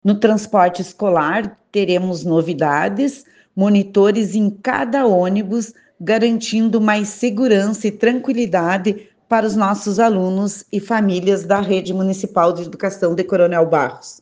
E a partir desse ano, o transporte escolar de Coronel Barros passa a contar com monitores, conforme explica a secretária de Educação. (Abaixo, sonora de Susana Wilde)